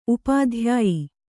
♪ upādhyāyi